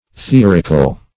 theorical - definition of theorical - synonyms, pronunciation, spelling from Free Dictionary Search Result for " theorical" : The Collaborative International Dictionary of English v.0.48: Theorical \The*or"ic*al\, a. Theoretic.